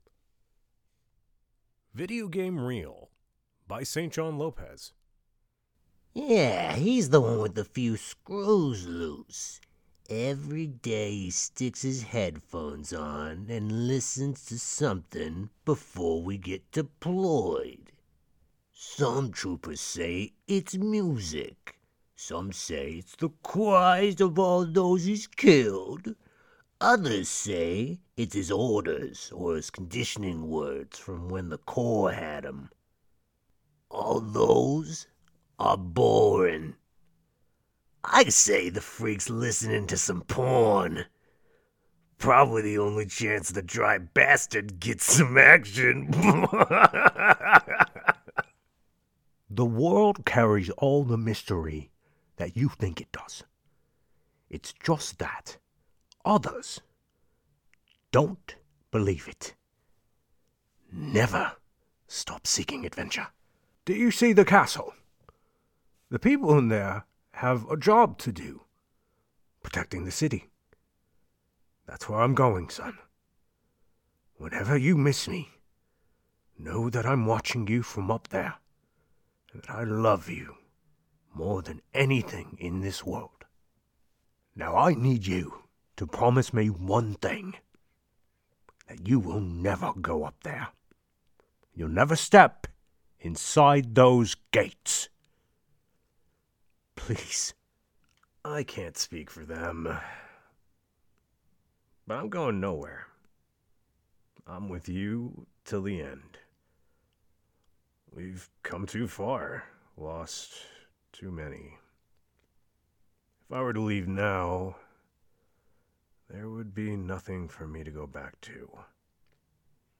Video Game Reel (2025) - Boisterous, Over-the-Top, Animated, Heightened Exclamations!
Video Game Reel.mp3